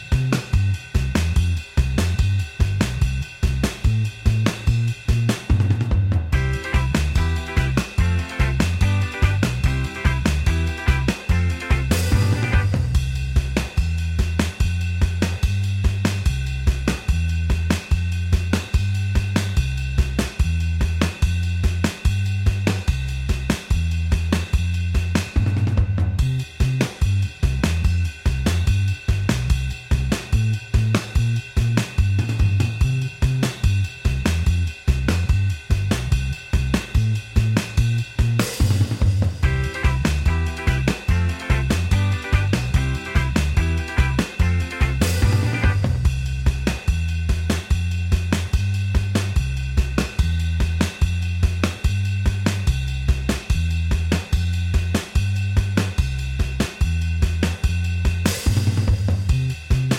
Minus Main Guitar For Guitarists 2:18 Buy £1.50